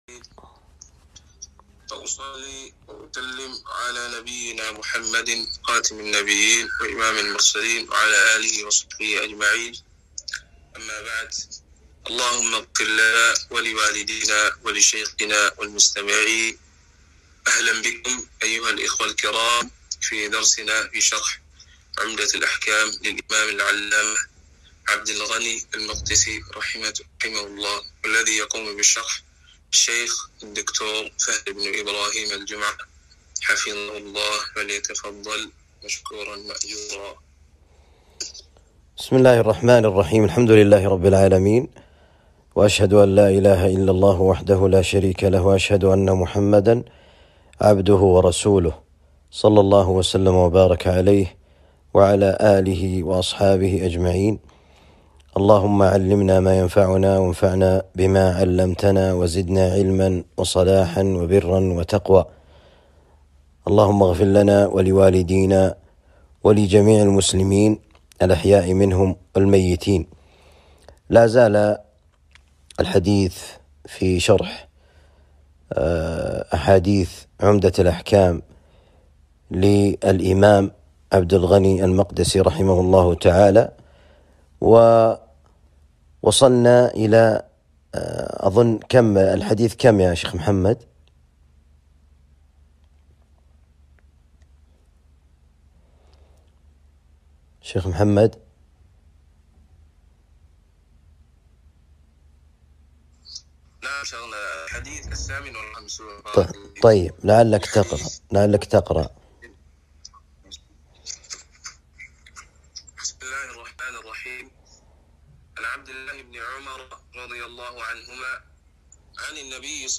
شرح عمدة الأحكام الدرس السابع عشر {١٧}